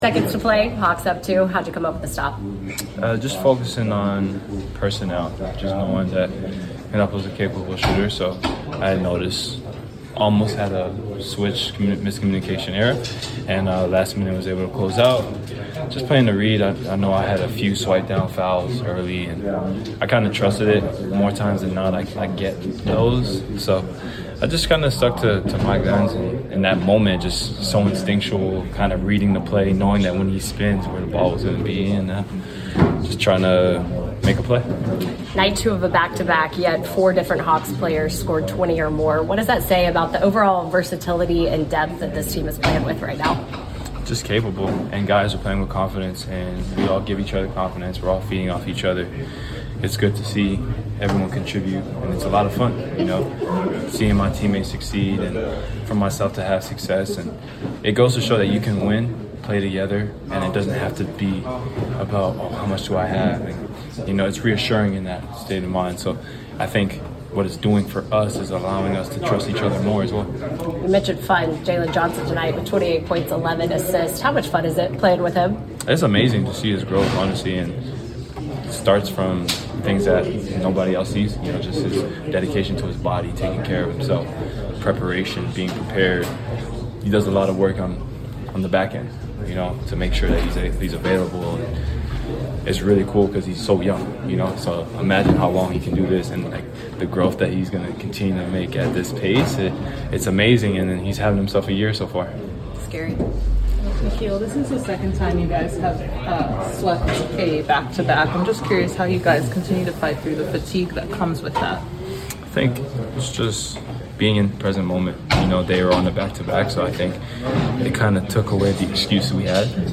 Atlanta Hawks Guard Nickeil Alexander-Walker Postgame Interview after defeating the Charlotte Hornets at State Farm Arena.